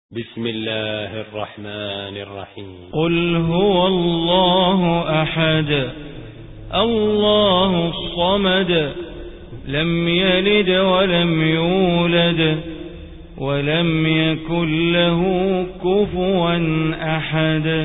Surah Ikhlas Recitation by Sheikh Bandar Baleela
Surah Ikhlas, listen online mp3 tilawat / recitation in Arabic in the beautiful voice of Imam e Kaaba Sheikh Bandar Baleela.